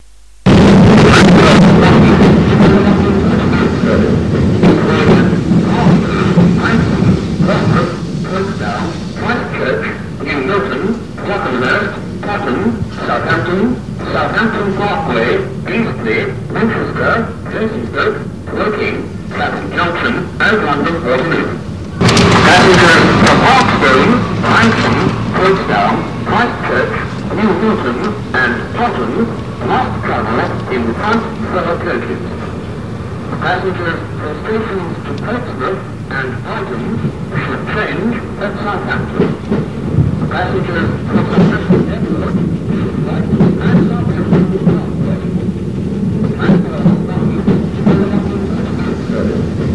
Poole pre-recorded platform announcement number 157 (year: 1989)